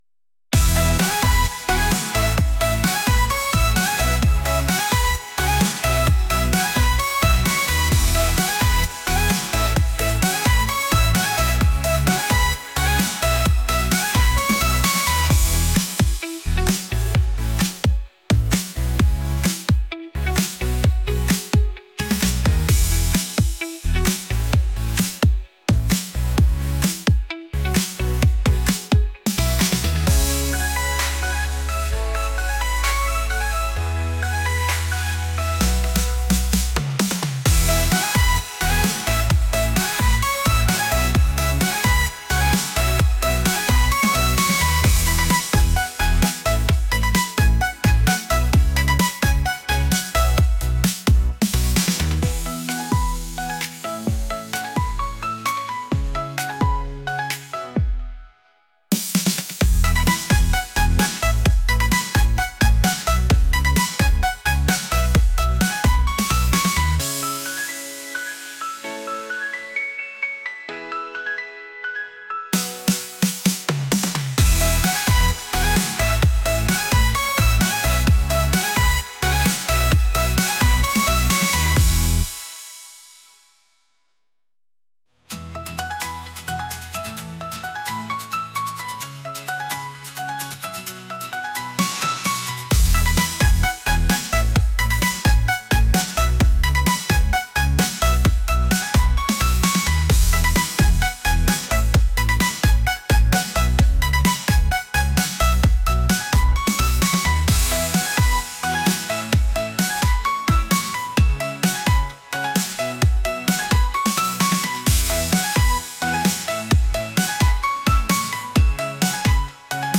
energetic | pop | catchy